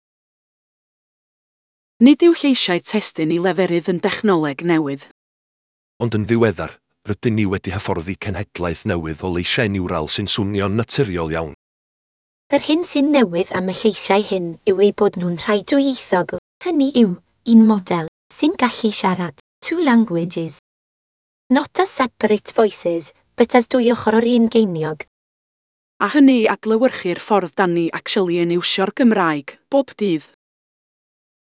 Gadael i'r dechnoleg siarad drosti'i hun...